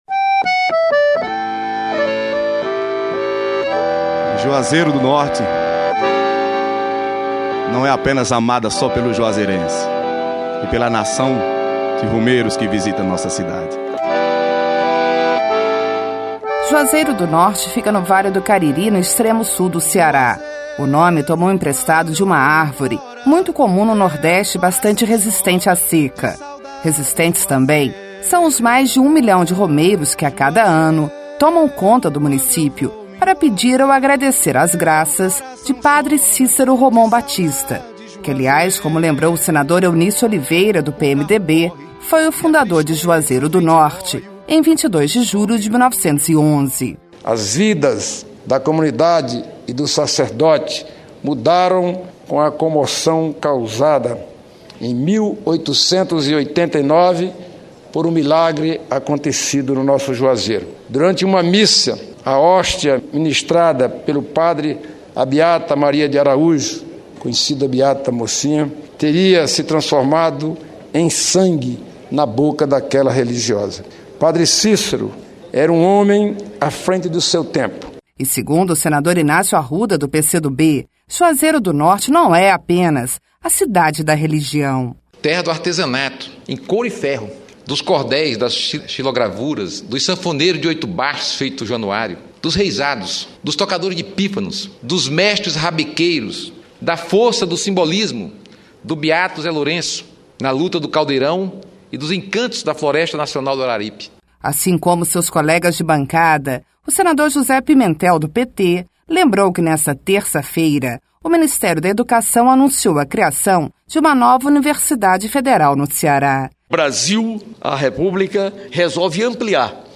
LOC: O SENADO HOMENAGEOU OS CEM ANOS DA CIDADE DE JUAZEIRO DO NORTE, NO CEARÁ. LOC: A HOMENAGEM ACONTECEU DURANTE O PERÍODO DO EXPEDIENTE, NO INÍCIO DA SESSÃO PLENÁRIA DESTA TERÇA-FEIRA.